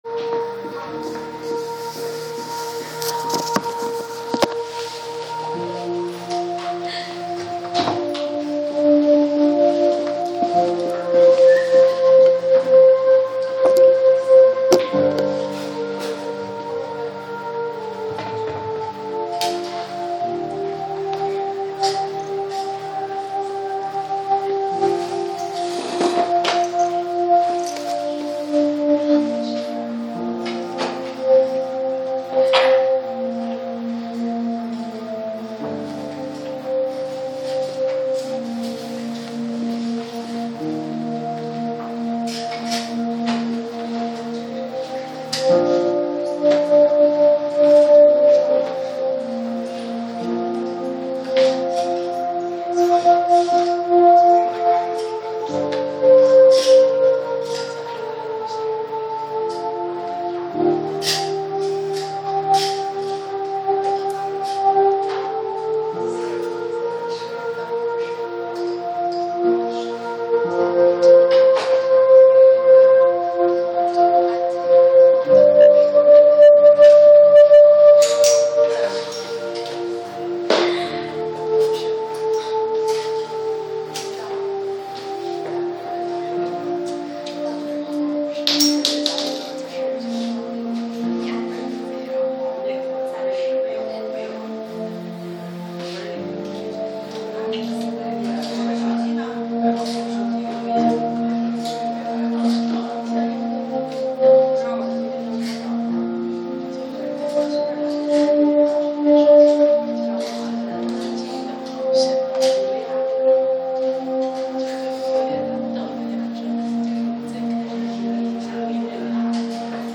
主日恩膏聚会